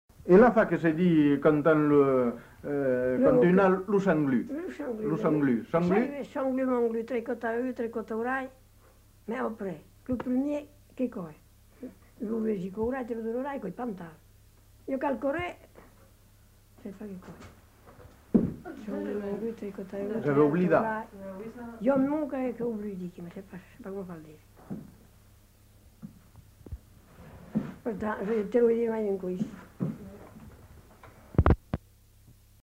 Aire culturelle : Périgord
Lieu : Cendrieux
Genre : forme brève
Type de voix : voix de femme
Production du son : récité
Classification : formulette